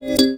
Casual Game Sounds U6